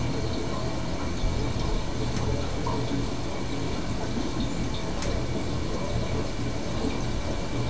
bath6.wav